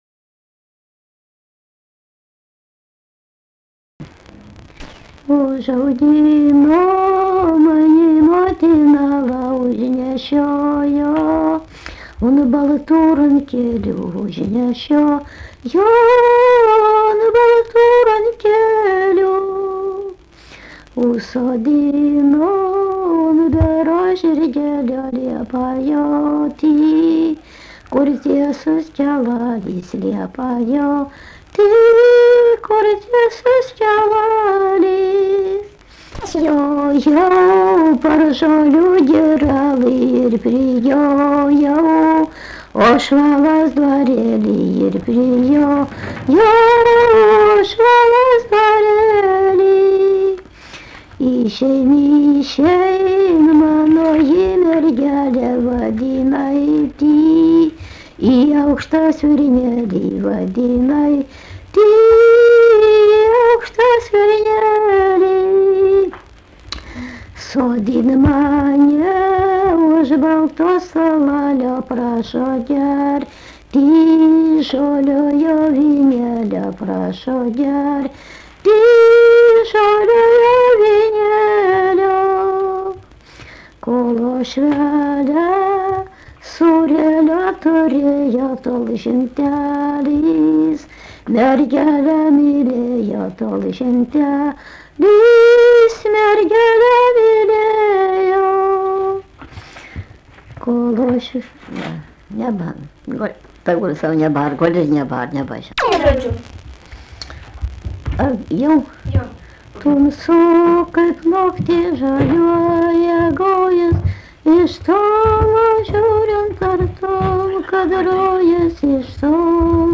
daina
Rėkučiai
vokalinis